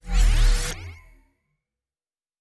Teleport 5_1.wav